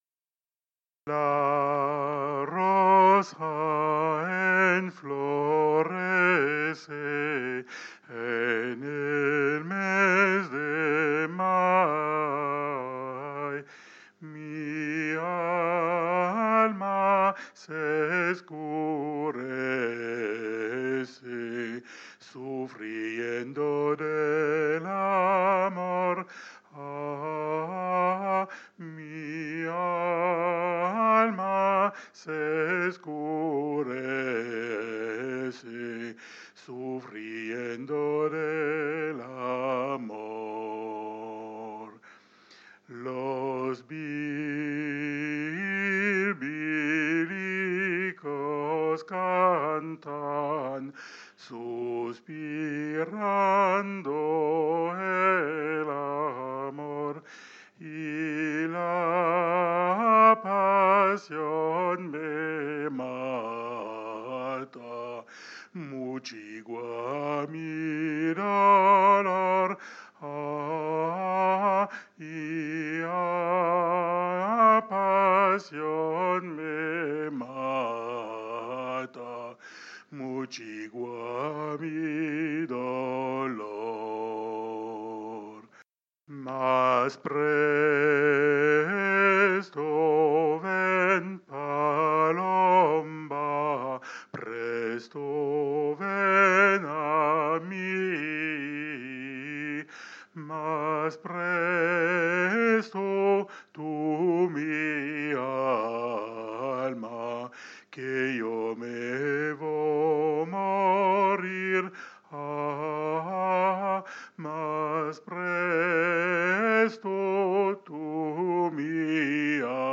La rosa enflorece 3v alto chanté, Alto chanté, MP3